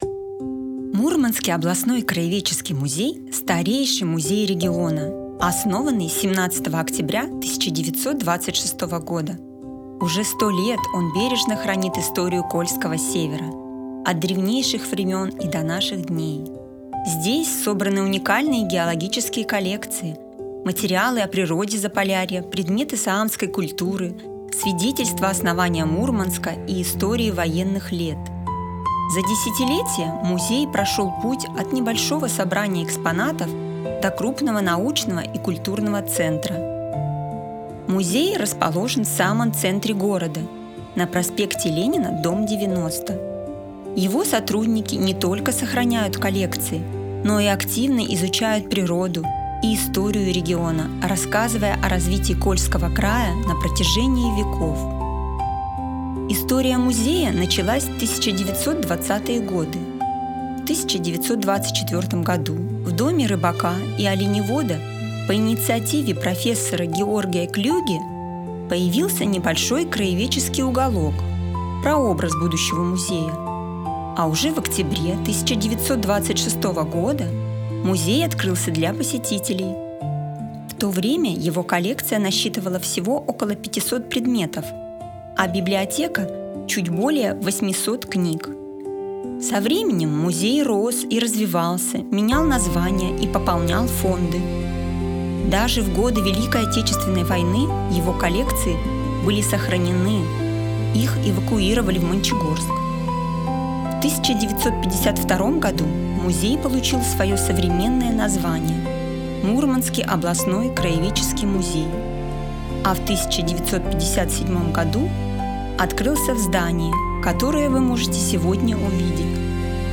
Аудиоэкскурсия о Краеведческом музее